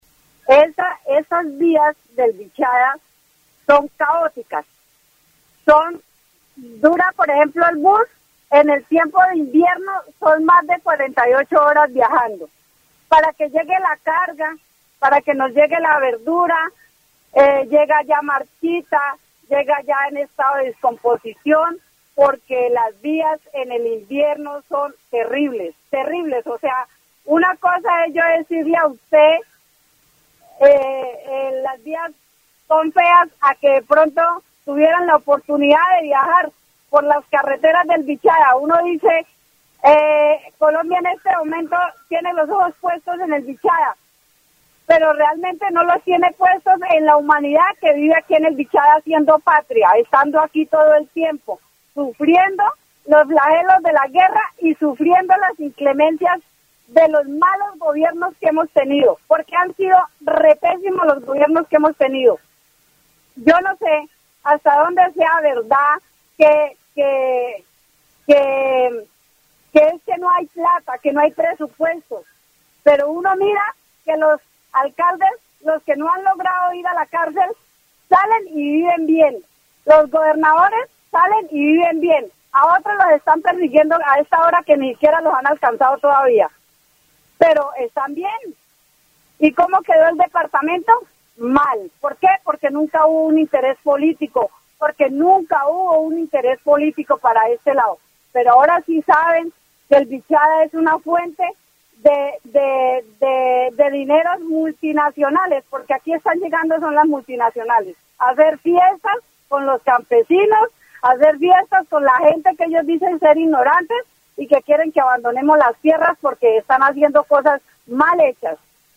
Vichada (Región, Colombia) -- Grabaciones sonoras , Programas de radio , Problemas socioeconómicos -- Vichada (Región, Colombia) , Infraestructura y transporte en zonas rurales , Corrupción y desinterés político -- Vichada (Región, Colombia)